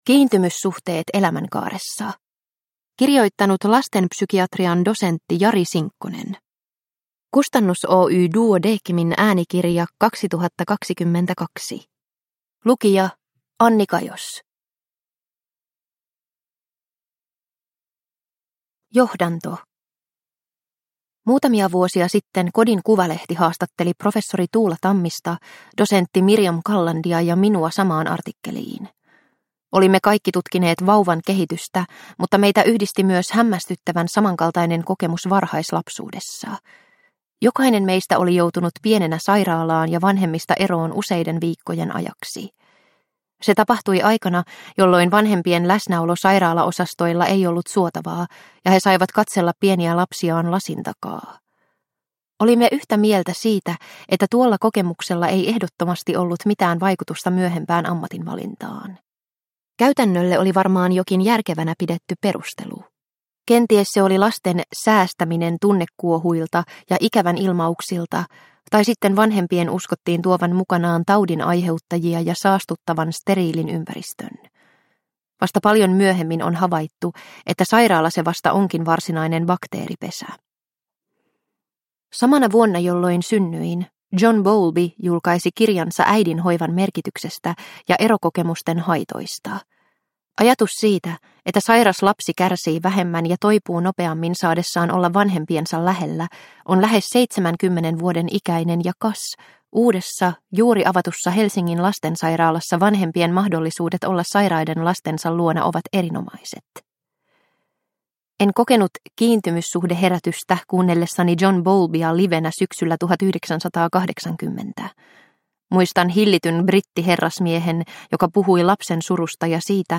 Kiintymyssuhteet elämänkaaressa – Ljudbok – Laddas ner